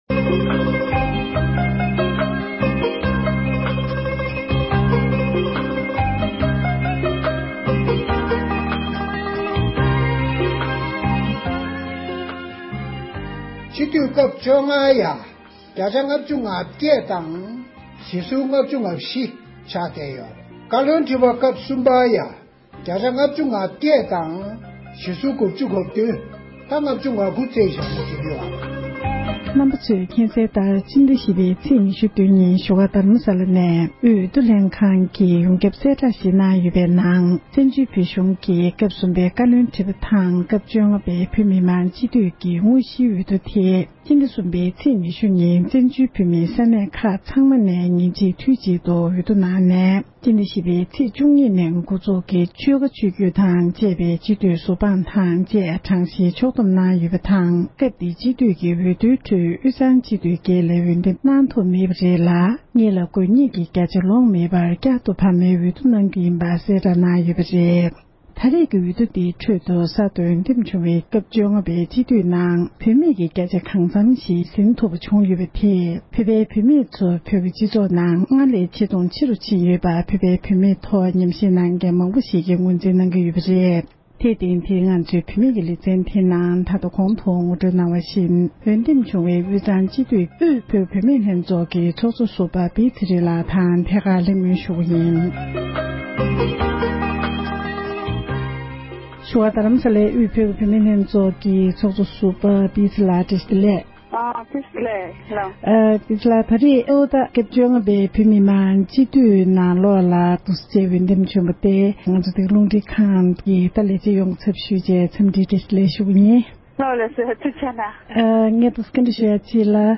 ཐད་ཀར་གནས་འདྲི་ཞུས་པ་ཞིག་ལ་གསན་རོགས་ཞུ༎